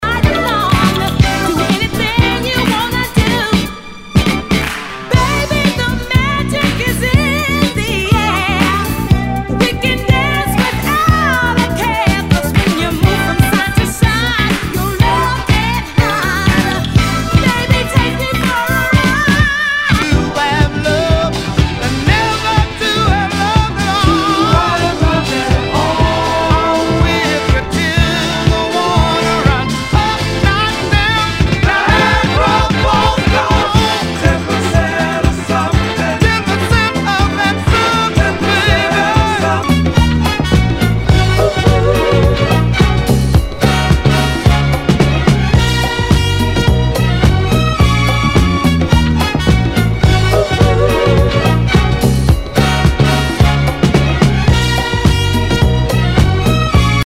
SOUL/FUNK/DISCO
ナイス！ダンス・クラシック！